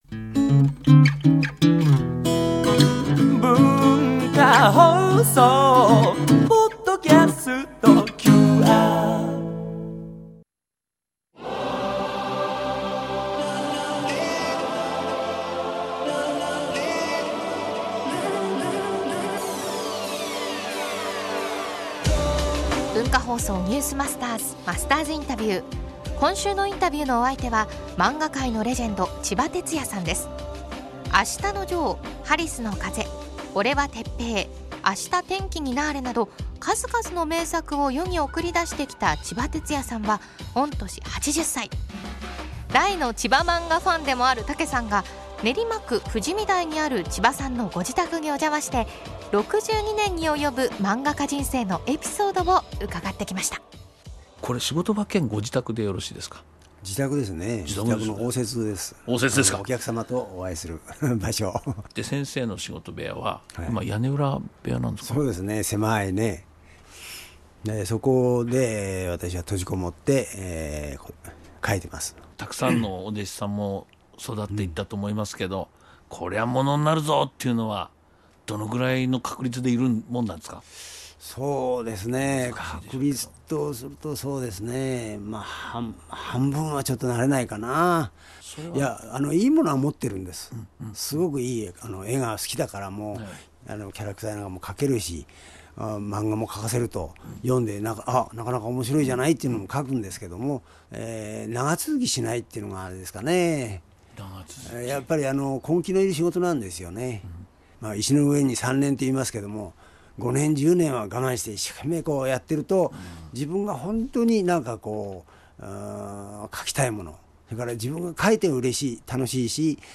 毎週、現代の日本を牽引するビジネスリーダーの方々から次世代につながる様々なエピソードを伺っているマスターズインタビュー。
今週のインタビューのお相手は漫画界のレジェンド　ちばてつやさんです。
（月）～（金）AM7：00～9：00　文化放送にて生放送！